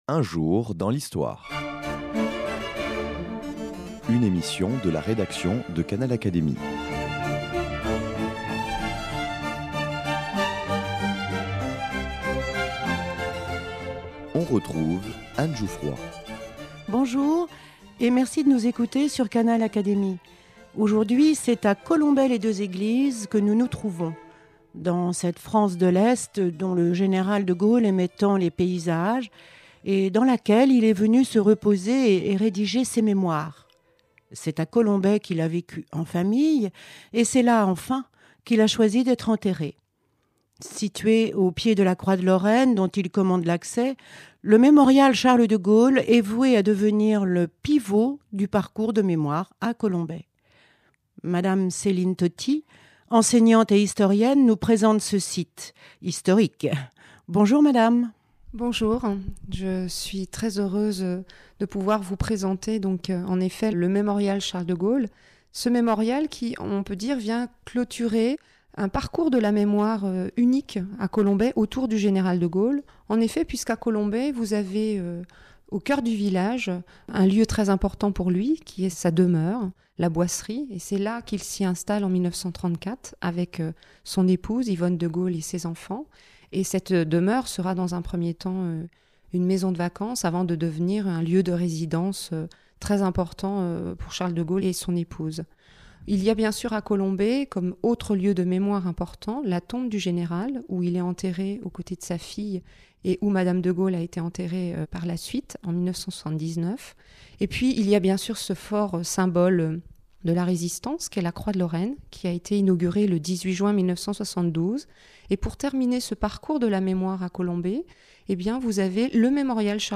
Reportage à Colombey-les-deux-Eglises